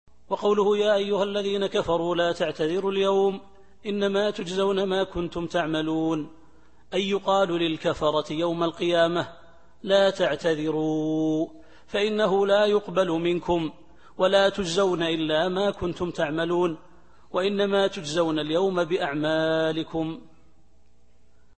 التفسير الصوتي [التحريم / 7]